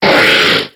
Cri de Vigoroth dans Pokémon X et Y.